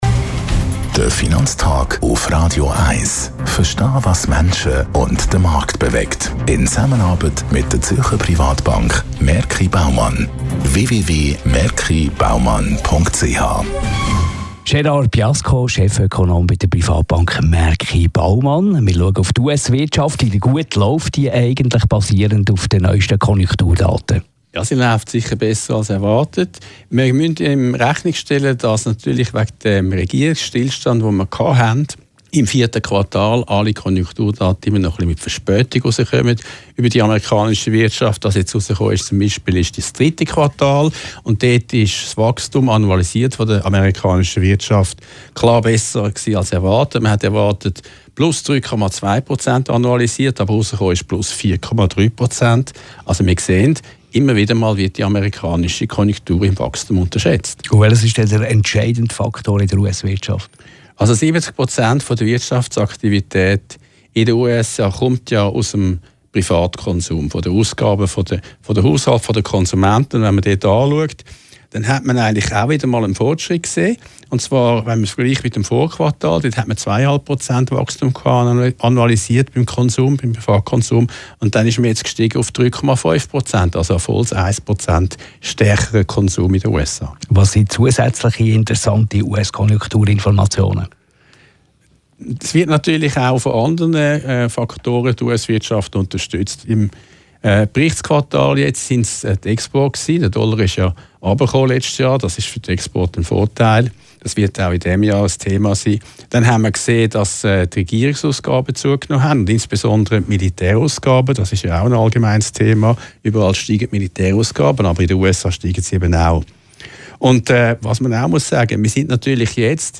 Die Radio 1-Experten.